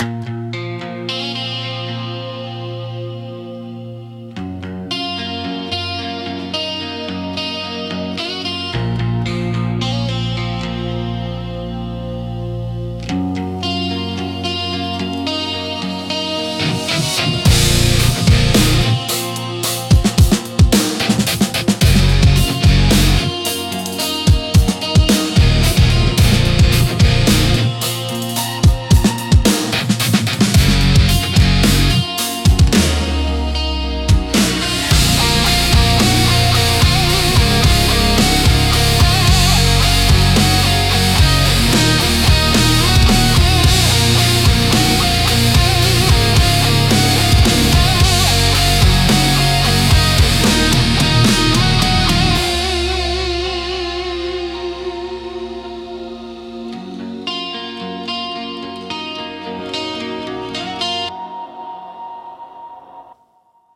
Instrumental - Resophonic Blues